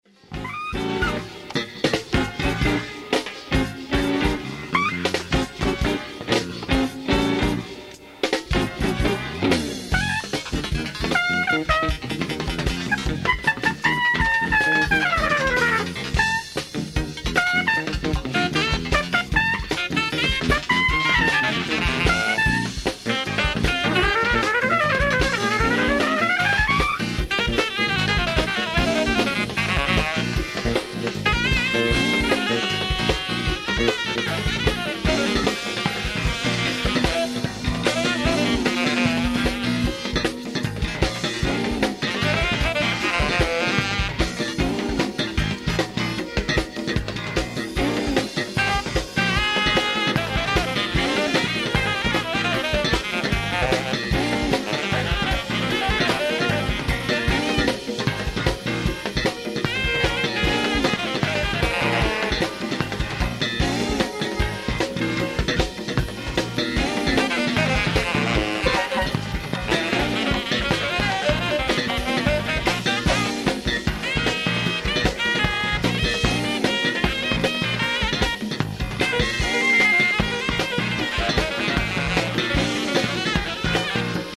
Live At Stadio Curri, Perugia, Italy July 7, 1985
COMPLETE SHOW AND BEST QUALITY EVER
FULL SOUNDBOARD RECORDING